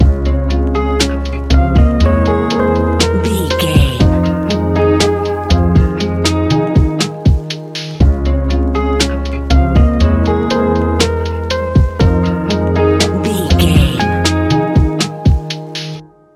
Ionian/Major
C♭
laid back
Lounge
new age
chilled electronica
ambient
instrumentals